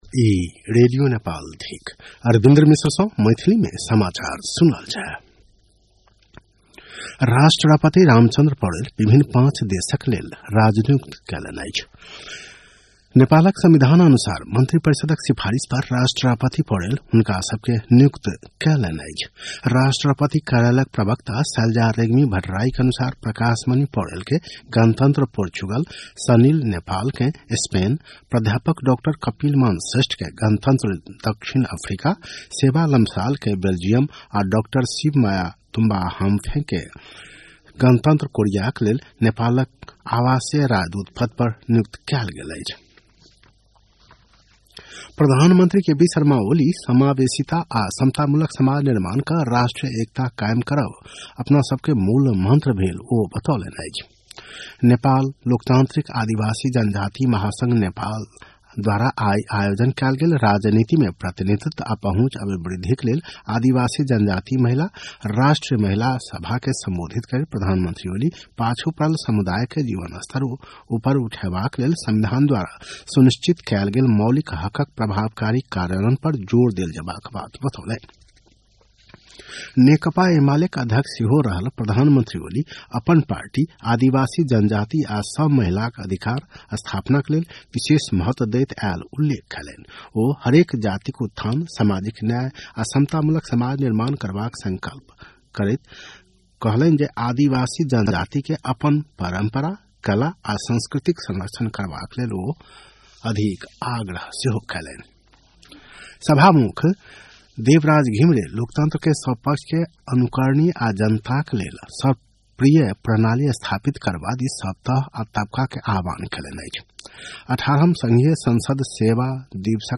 मैथिली भाषामा समाचार : २९ मंसिर , २०८१
6-pm-maithali-news-8-28.mp3